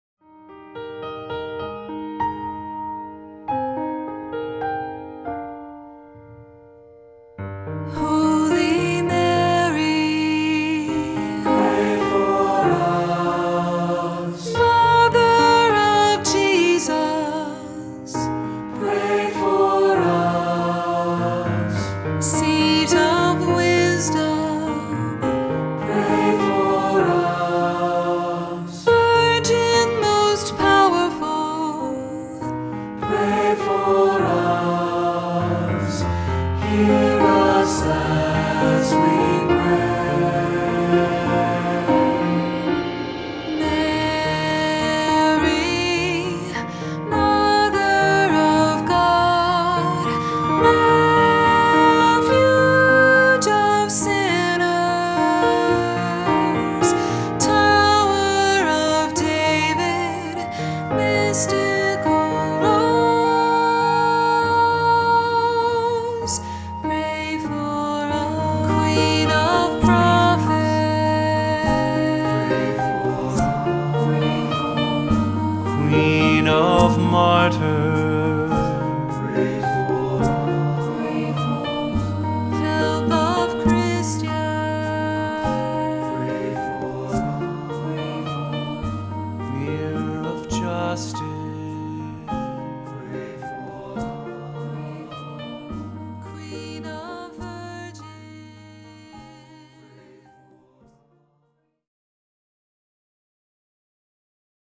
Voicing: Assembly, cantor,SATB